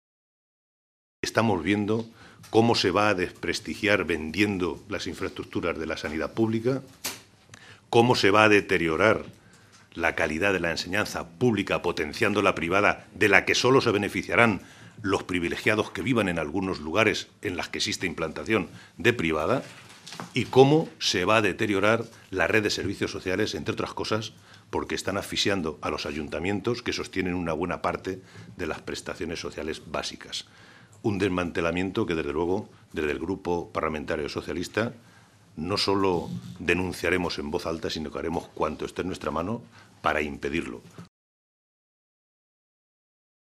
Santiago Moreno, diputado regional del PSOE de Castilla-La Mancha
Cortes de audio de la rueda de prensa